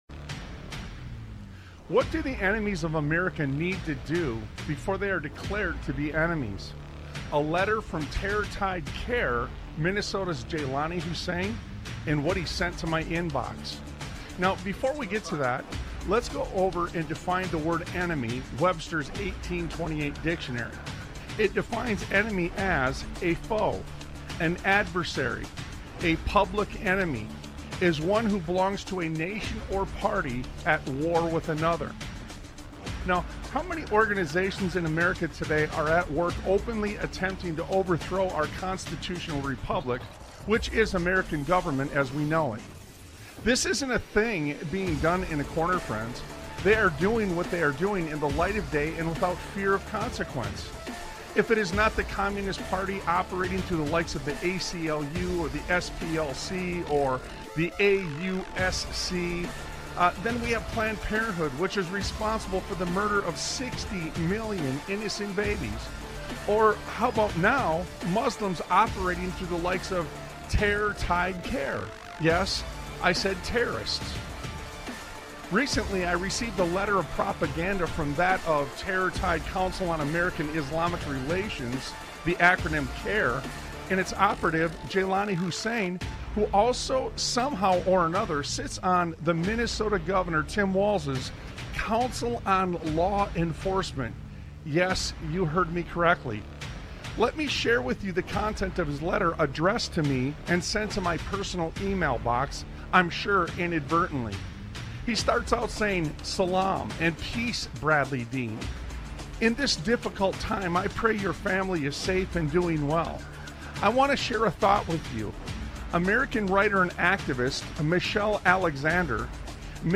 Talk Show Episode, Audio Podcast, Sons of Liberty Radio and What Is Missing Here? on , show guests , about What Is Missing Here, categorized as Education,History,Military,News,Politics & Government,Religion,Christianity,Society and Culture,Theory & Conspiracy